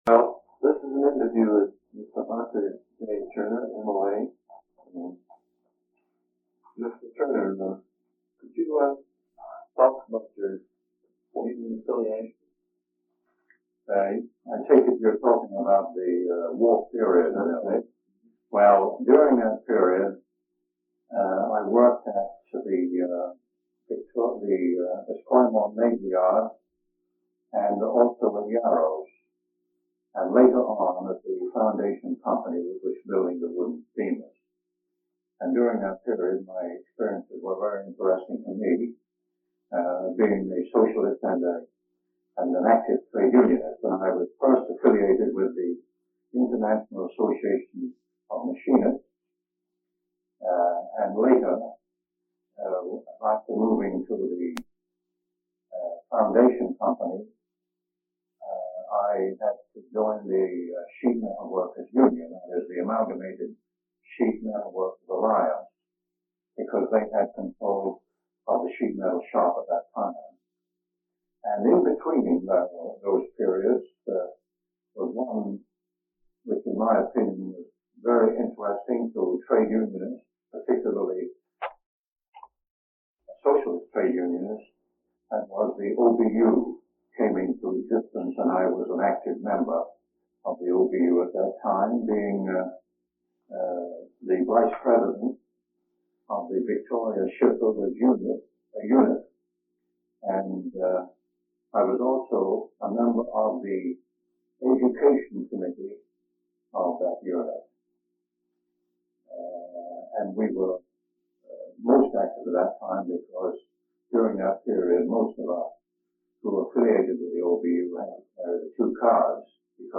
In this interview he describes the origins, rise and decline of the One Big Union, as well as various labour-socialist parties in British Columbia. He relates an incident at a large anti-conscription meeting in Victoria during World War One that was broken up by soldiers, and another when Quebec conscripts were forced at gunpoint to board a ship in Victoria for the Russian front during World War One.